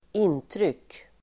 Uttal: [²'in:tryk:]